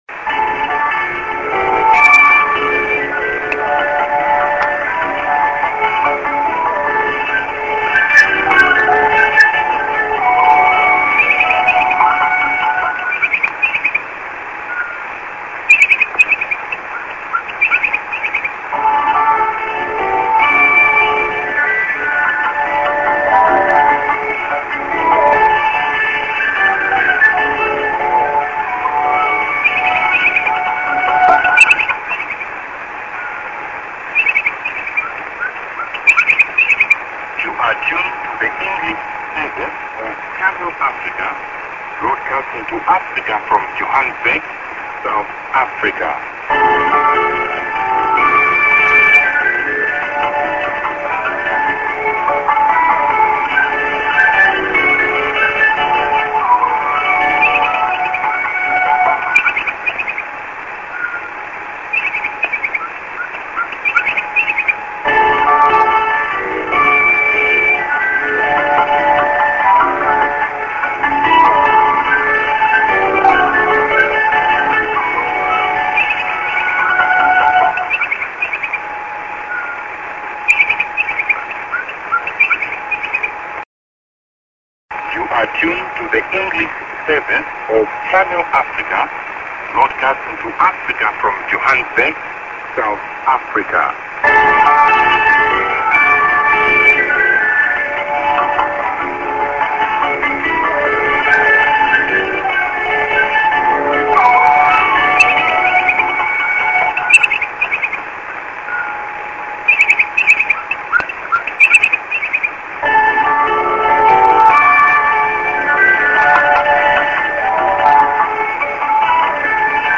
ENG. St. IS+ID(man+man)->ST(duram)->02'38":TS->music->03'10":ID+SKJ(man)